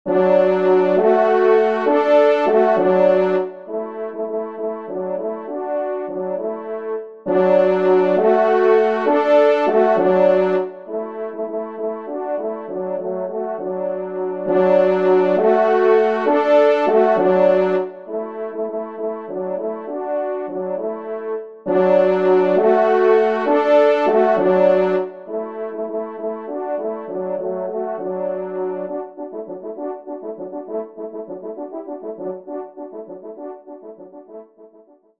2e Trompe
Millescamps-AFT-10-Indiana_TRP-2_EXT.mp3